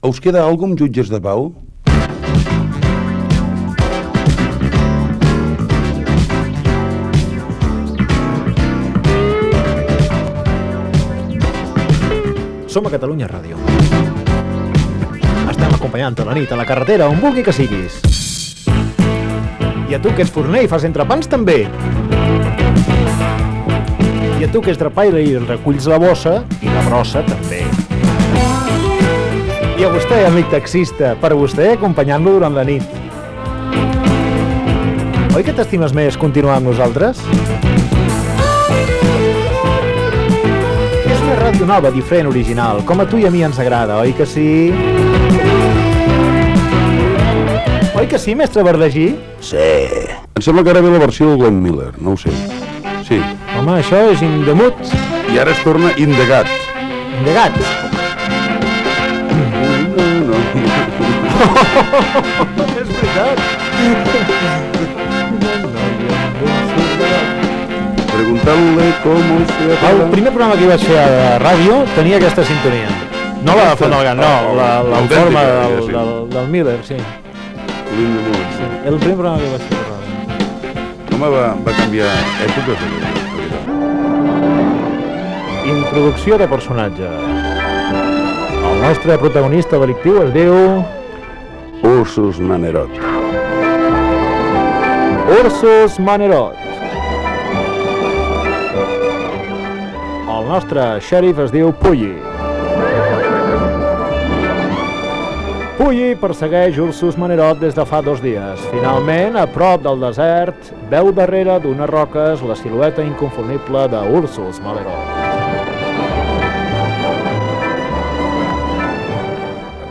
Entreteniment
Presentador/a
FM